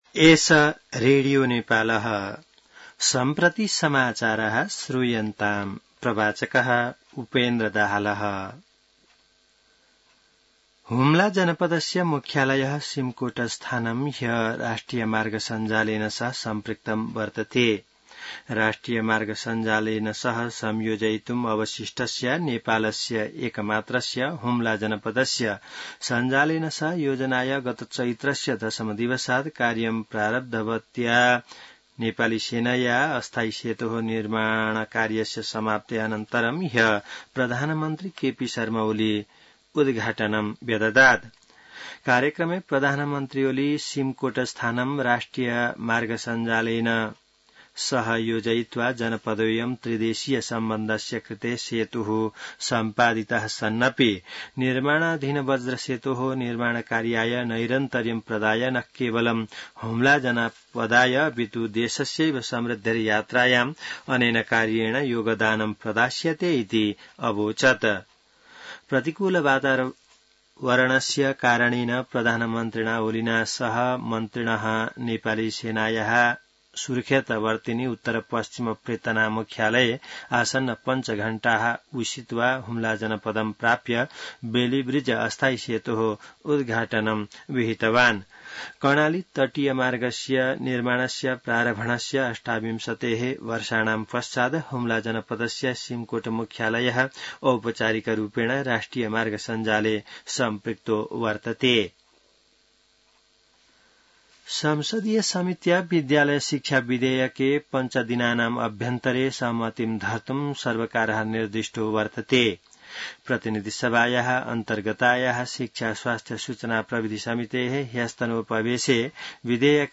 An online outlet of Nepal's national radio broadcaster
संस्कृत समाचार : २३ असार , २०८२